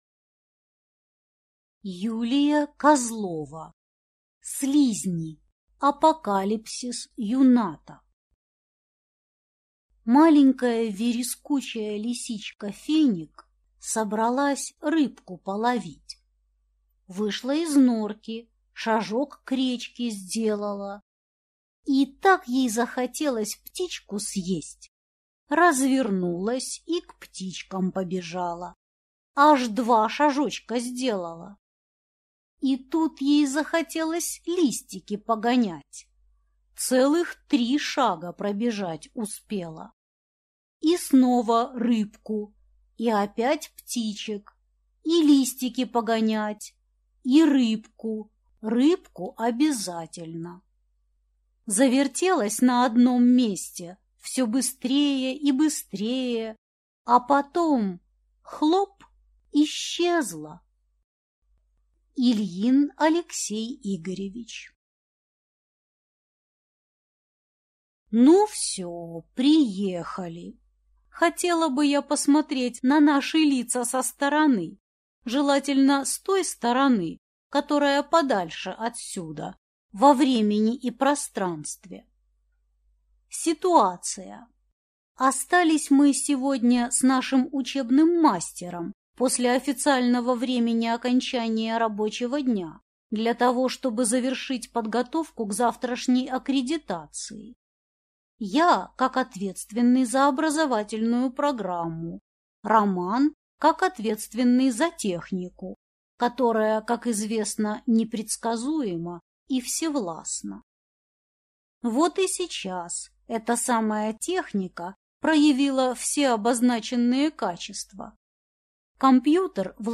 Аудиокнига Слизни. Апокалипсис юнната | Библиотека аудиокниг